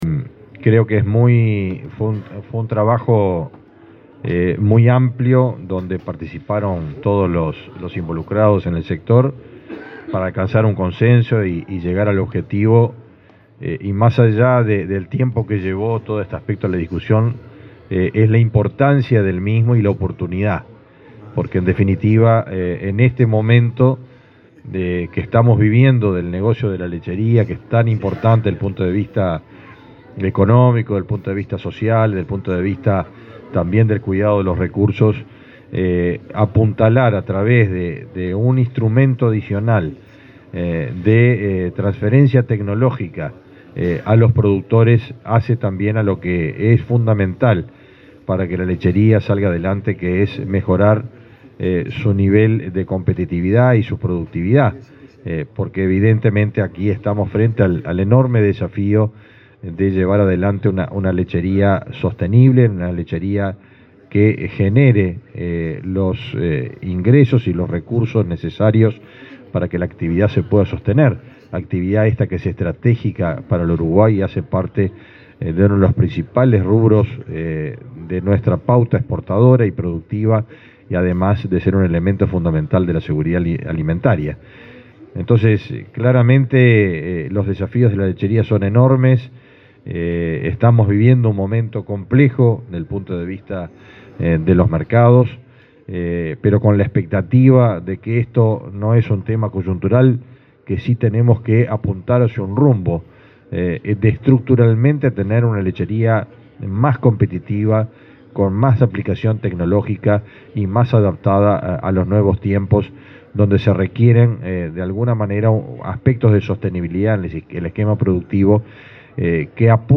Palabras del ministro de Ganadería, Fernando Mattos
Palabras del ministro de Ganadería, Fernando Mattos 14/09/2023 Compartir Facebook X Copiar enlace WhatsApp LinkedIn Este jueves 14 en la Expo Prado, el ministro de Ganadería, Fernando Mattos, participó de la firma de un convenio entre autoridades del Instituto Nacional de Investigación Agropecuaria (INIA) y el Instituto Nacional de la Leche (Inale).